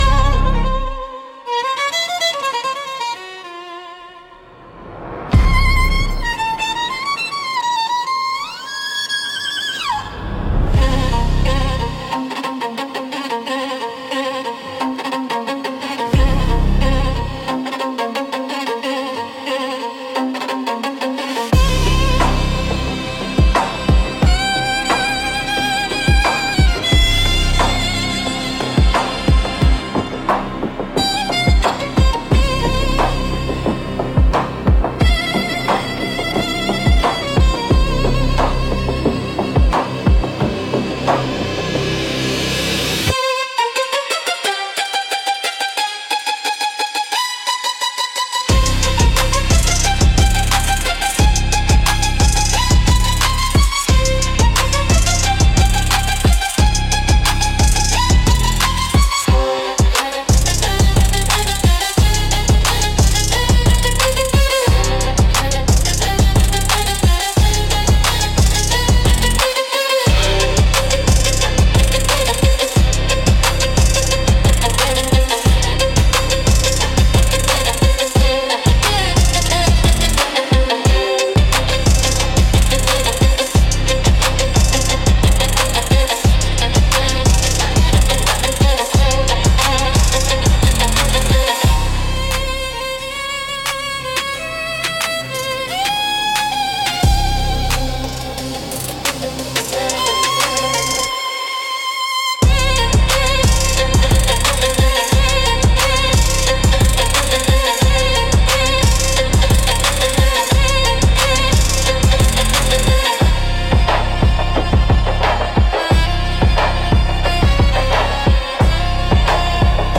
Instrumental - Southern Gothic x Dark Romantic Blues